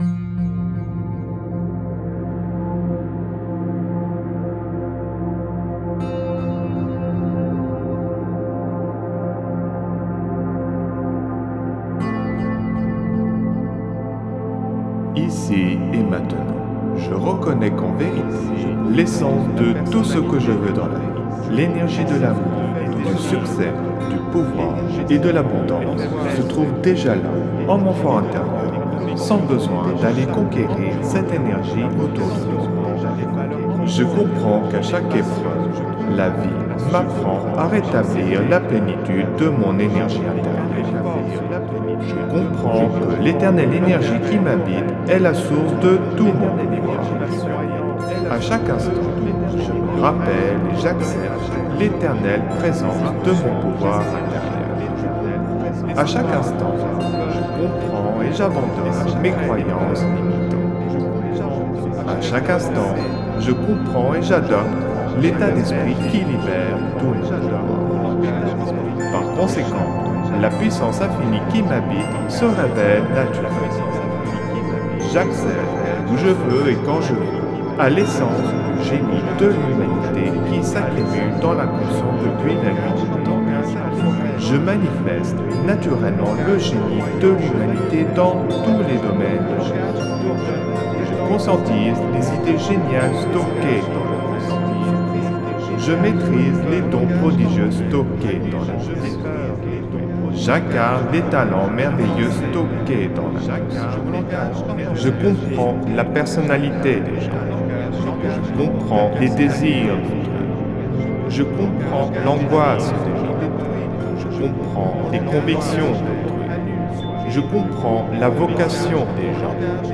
(Version ÉCHO-GUIDÉE)
Alliage ingénieux de sons et fréquences curatives, très bénéfiques pour le cerveau.
Pures ondes gamma intenses 64,61 Hz de qualité supérieure. Puissant effet 3D subliminal écho-guidé.